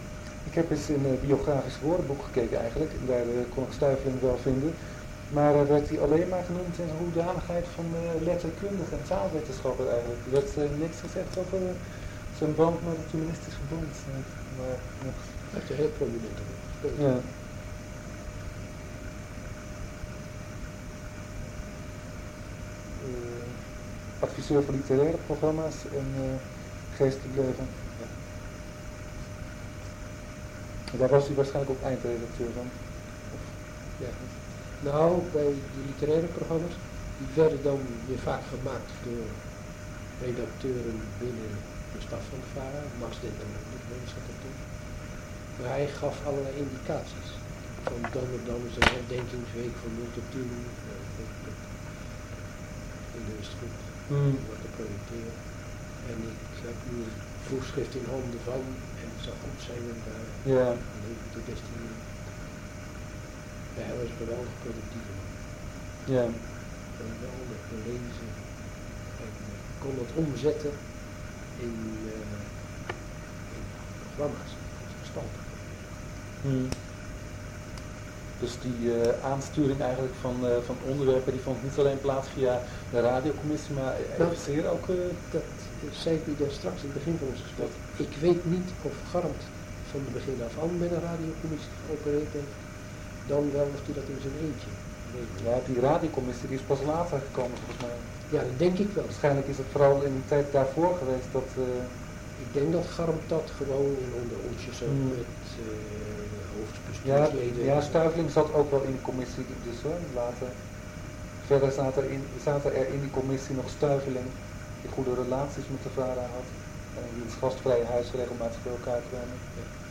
Interview naar aanleiding van het radioprogramma Woord van de Week.